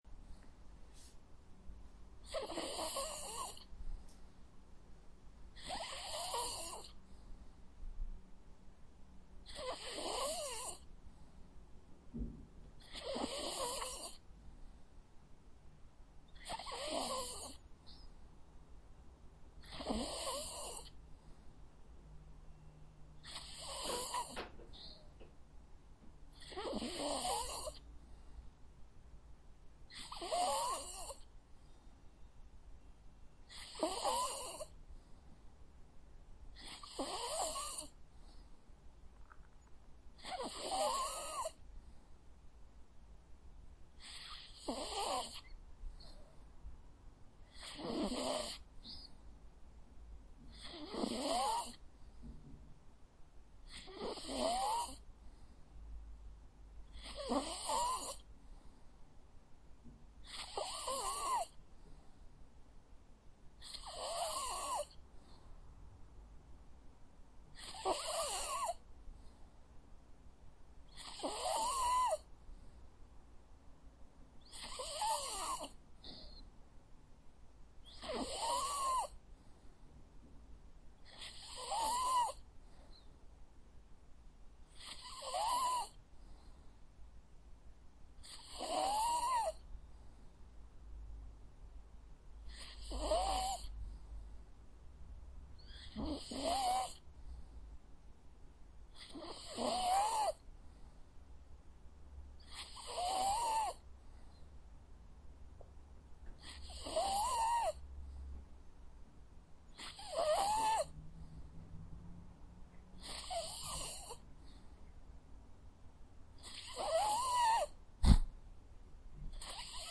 Sleeping Dog 2 Sound Button - Free Download & Play